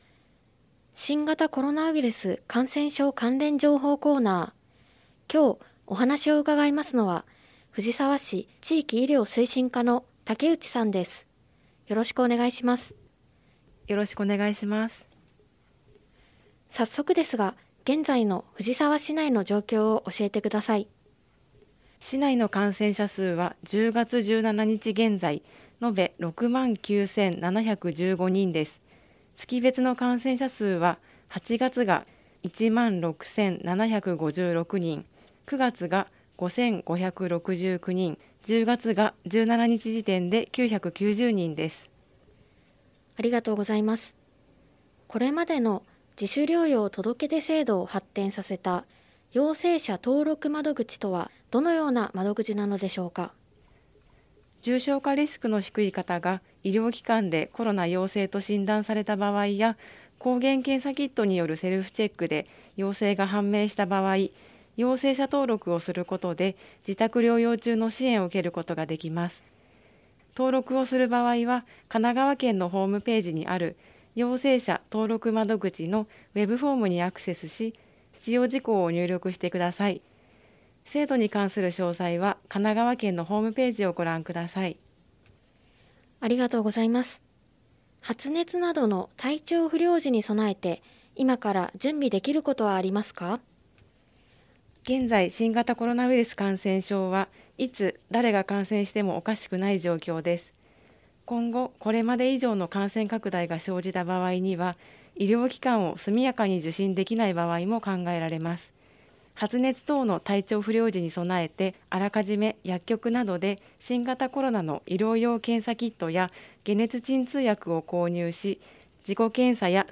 令和4年度に市の広報番組ハミングふじさわで放送された「新型コロナウイルス関連情報」のアーカイブを音声にてご紹介いたします。